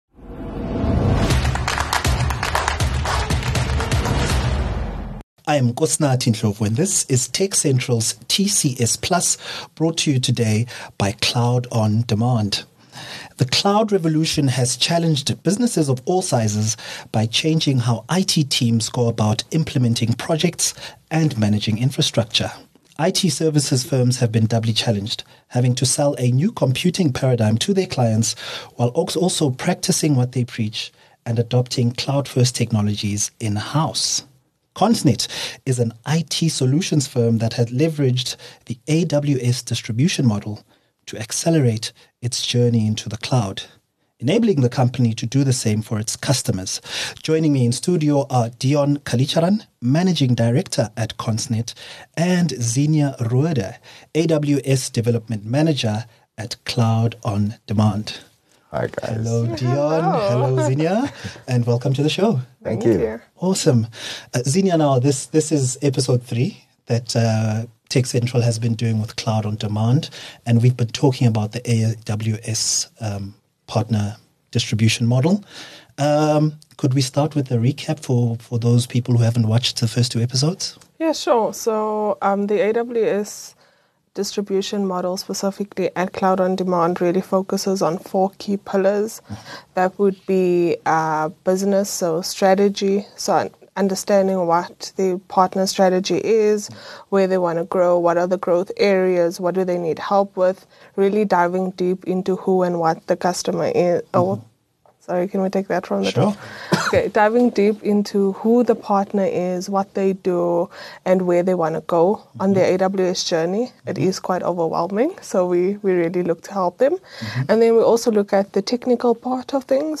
Don’t miss this informative conversation!